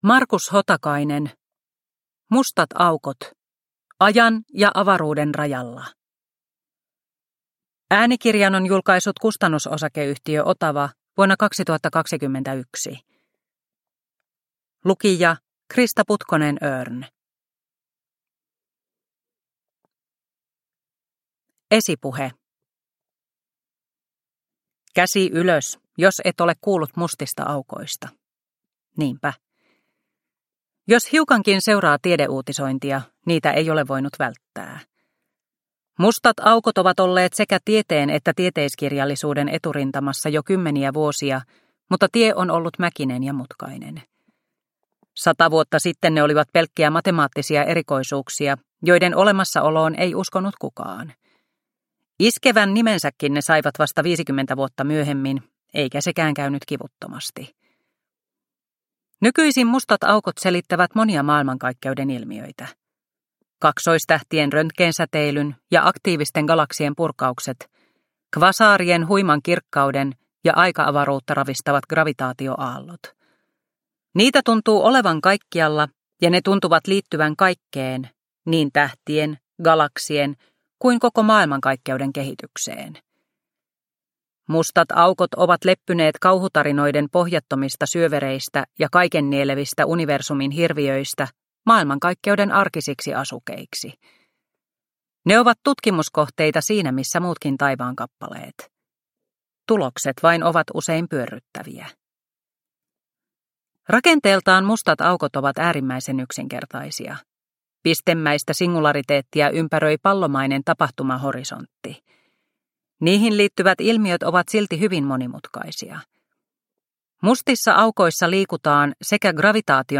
Mustat aukot – Ljudbok – Laddas ner